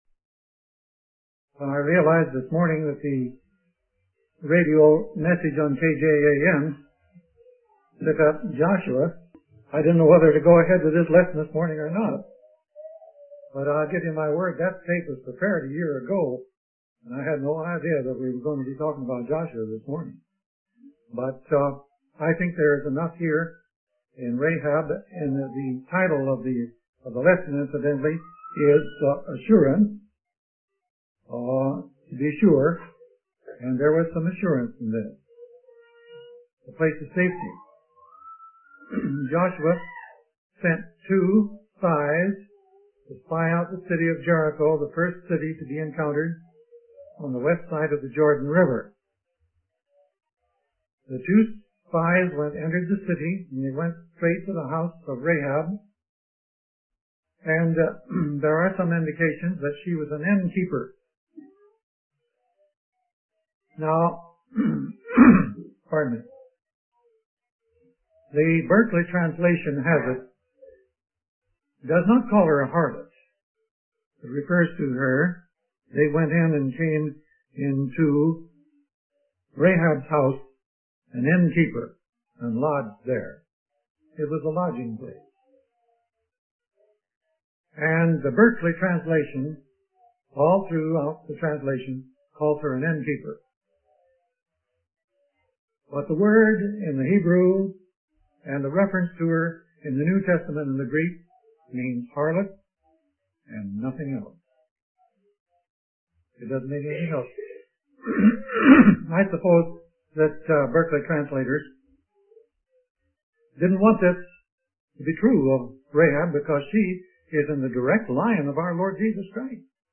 In this sermon, the preacher discusses the story of Rahab and how her faith saved her and her family. He emphasizes the importance of having faith in God's promises and the assurance of safety that comes from the Lord.